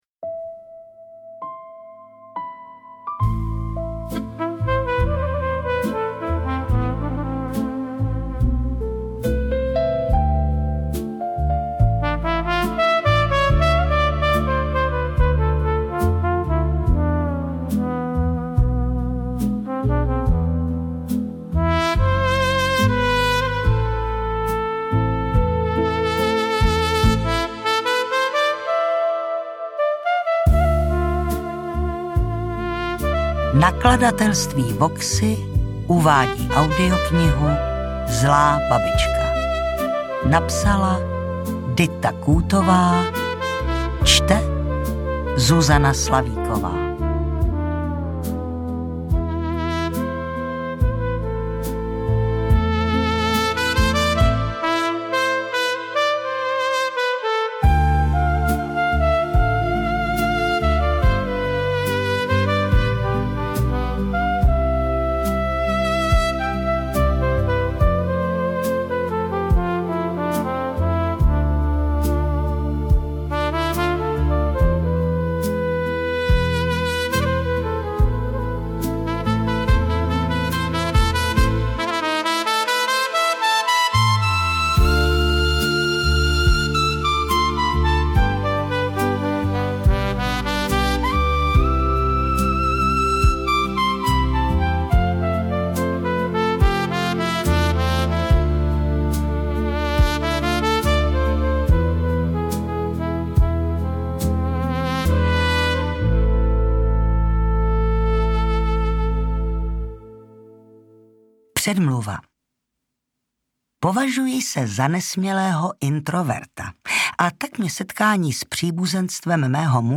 Interpret:  Zuzana Slavíková
AudioKniha ke stažení, 62 x mp3, délka 8 hod. 55 min., velikost 486,1 MB, česky